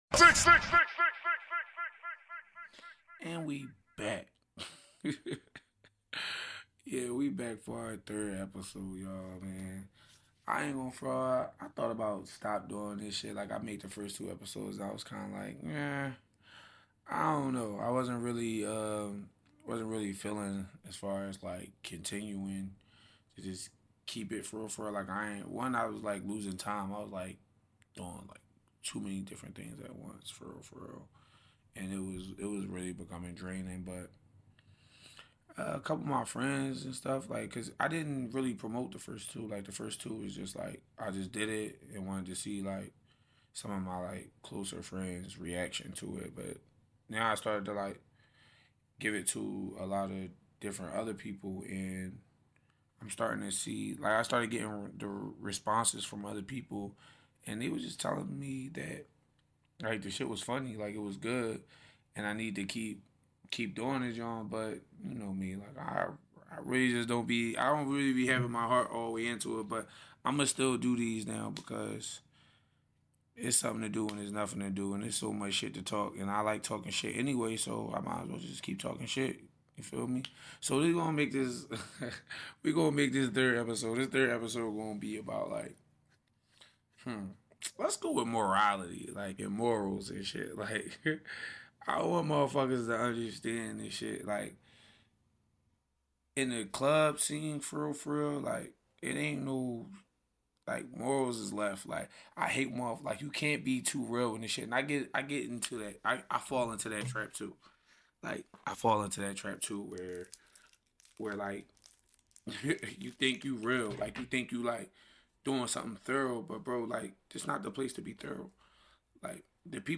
Zone 6 Radio will cover various topics surrounding the nightlife. We will interview different people surrounding the night life, Also give the Do's & Don'ts while participating in at all levels.